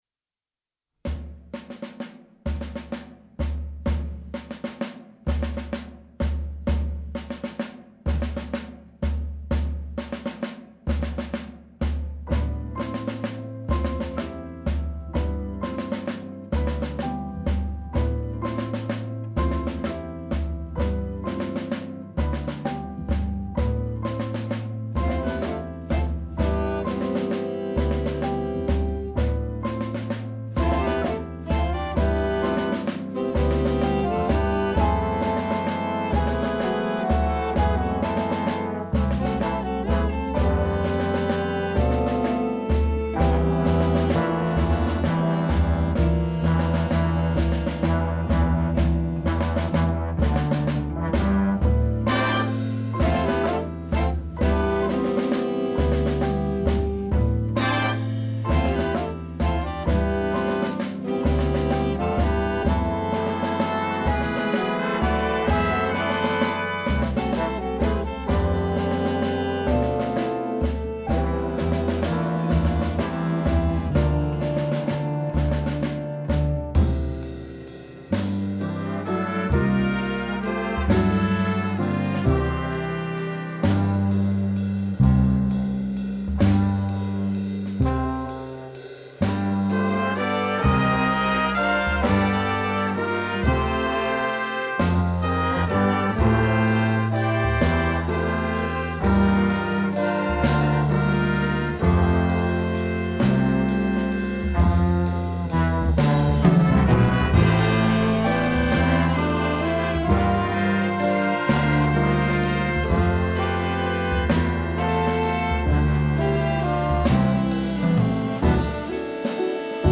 FOR BIG BAND
Category: Big Band